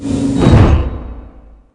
miningdronesreturn.ogg